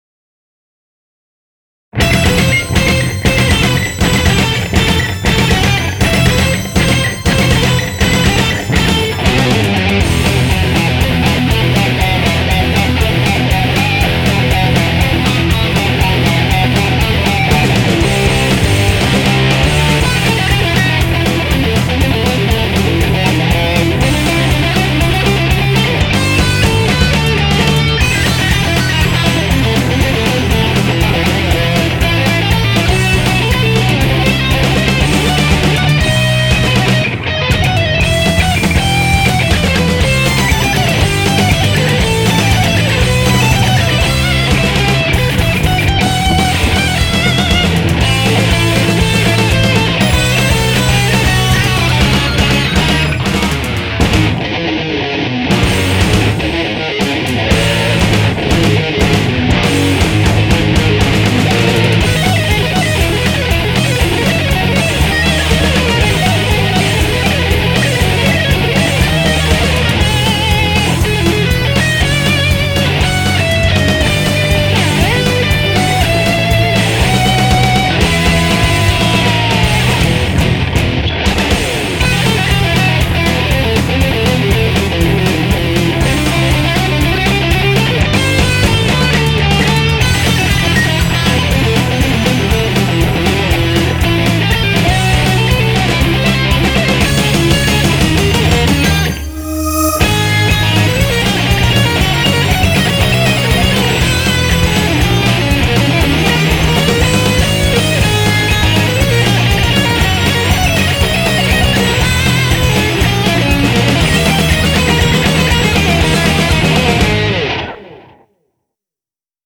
BPM240
Audio QualityPerfect (High Quality)
Comentarios[HARD ROCK]